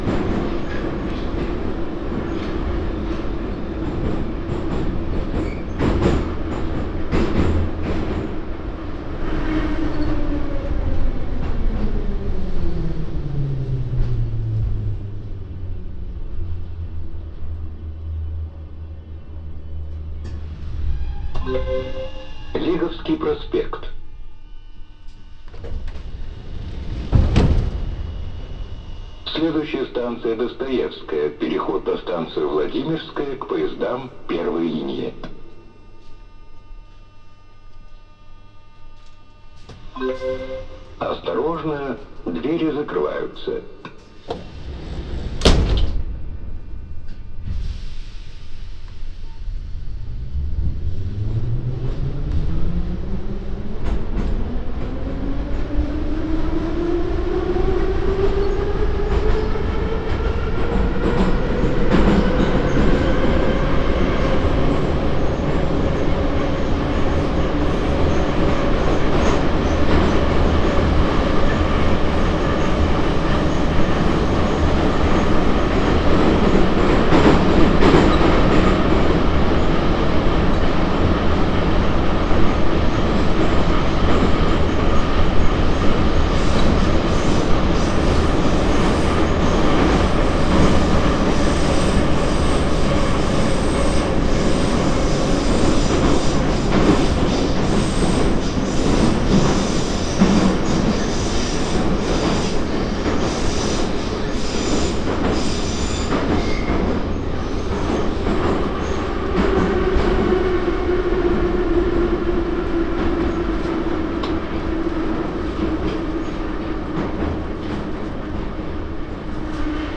3D spatial surround sound "Saint Petersburg Metro"
3D Spatial Sounds